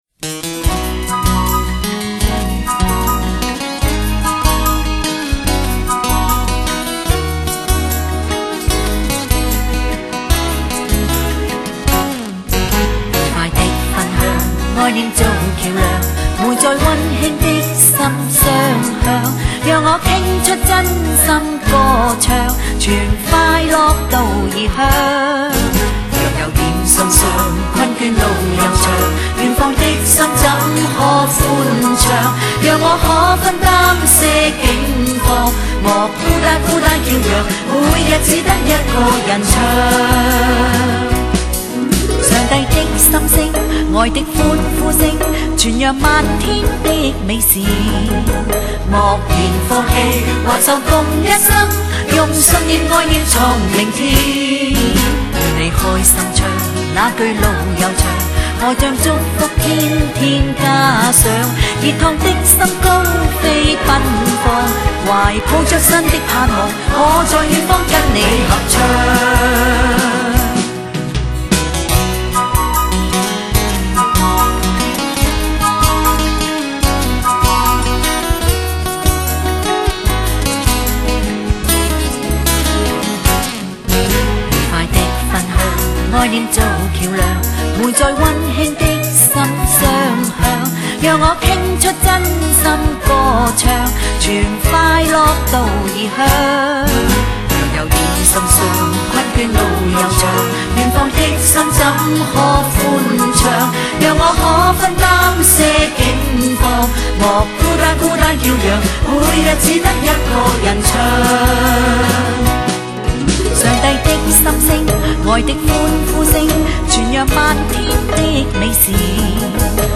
儿童赞美诗 | 愉快的分享